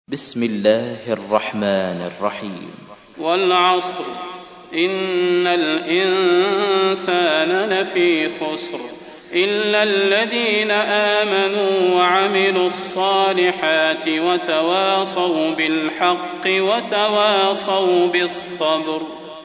تحميل : 103. سورة العصر / القارئ صلاح البدير / القرآن الكريم / موقع يا حسين